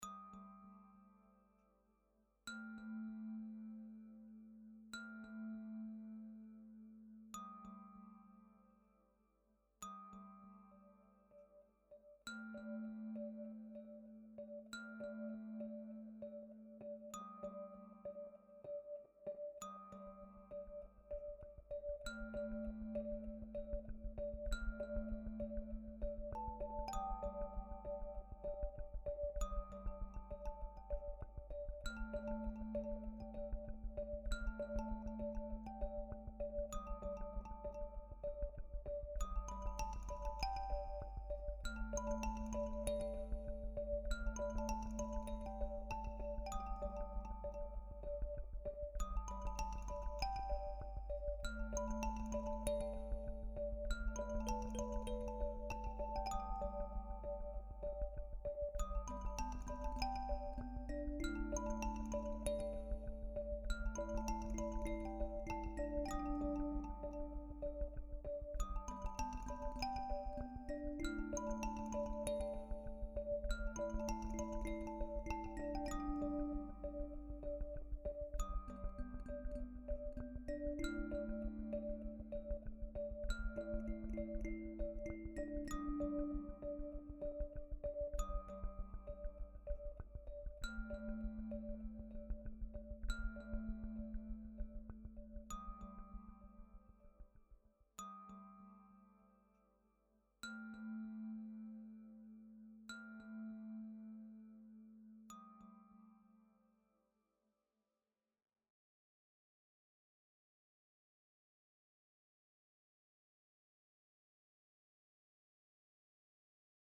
Pick-up-bolf-kalimba.mp3